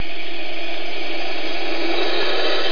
دانلود صدای مار کبری برای کودکان از ساعد نیوز با لینک مستقیم و کیفیت بالا
جلوه های صوتی